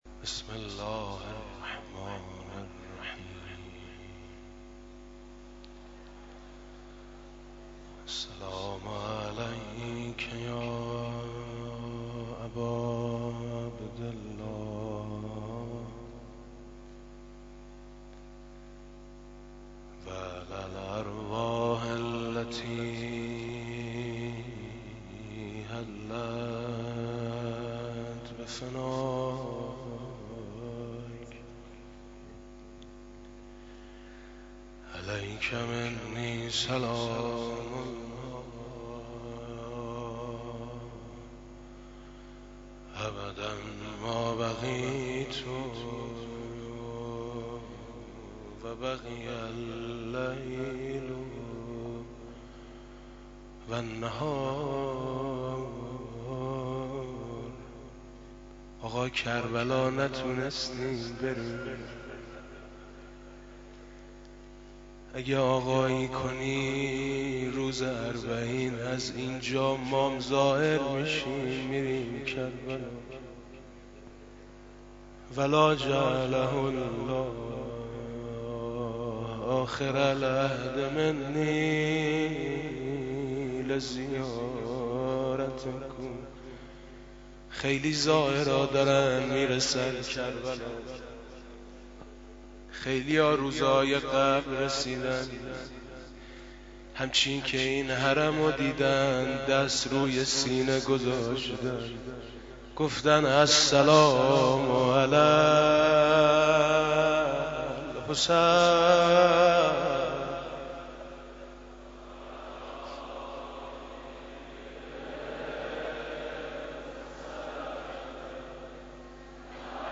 صوت كامل مداحی امروز آقای مطیعی در مراسم عزاداری اربعین حسینی در حضور رهبرانقلاب. ۹۵/۸/۳۰